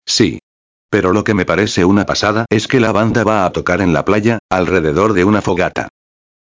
1- Escute com atenção o diálogo entre dois amigos de Alberta.
Diálogo #1